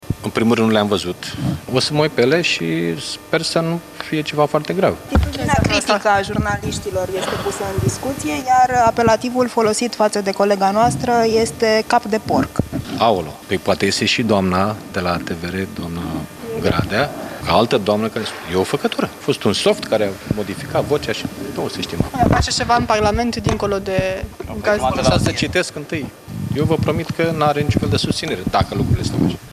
La Parlament, jurnaliștii i-au cerut șefului PSD să comenteze situația – de ce lui?
Însă Liviu Dragnea nu s-a putut abține să răspundă în bășcălie la întrebările care i-au fost adresate pe tema insultelor proferate de șeful televiziunii la adresa reporterilor.